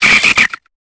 Cri de Pifeuil dans Pokémon Épée et Bouclier.